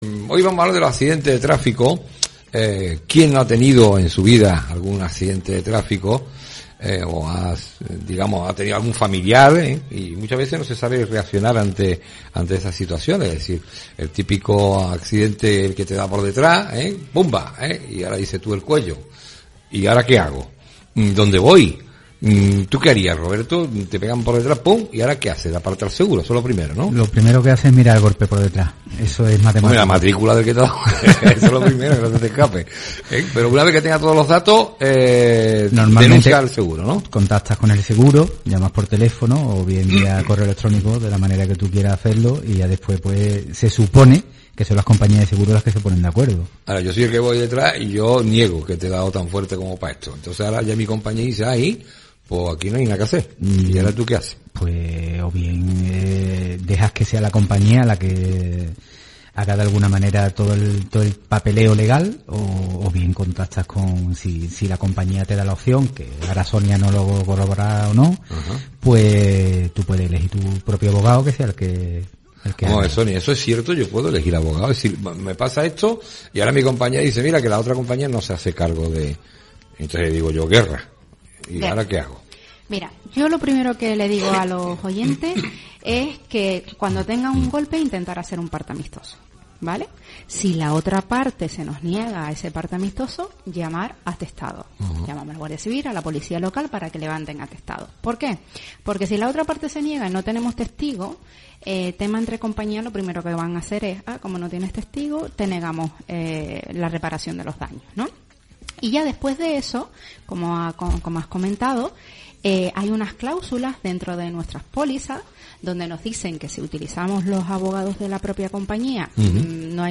Accidentes-de-trafico-consumodetalle11marzointervencionso-radioguadalquivir.mp3